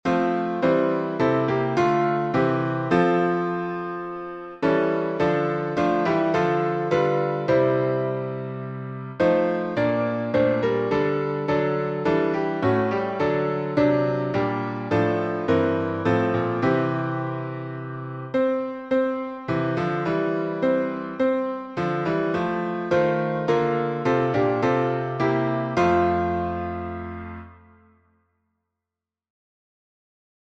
#6036: Rejoice, Ye Pure in Heart — F major | Mobile Hymns